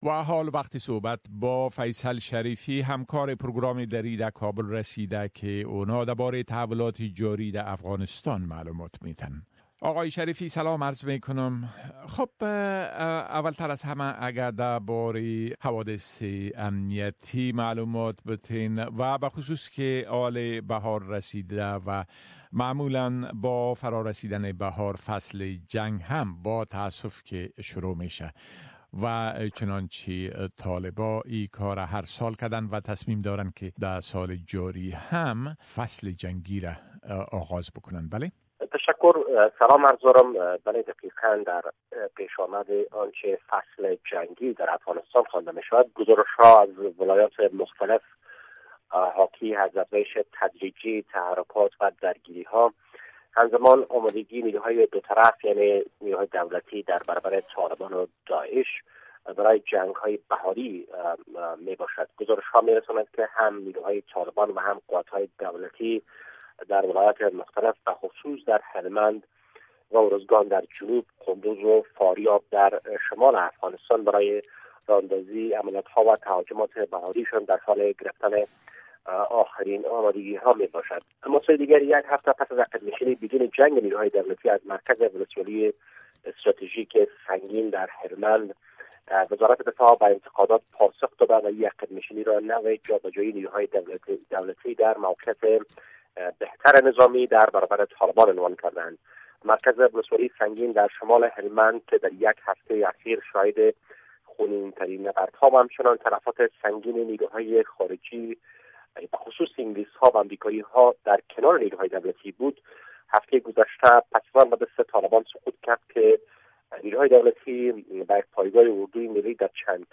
A report from our correspondent in Afghanistan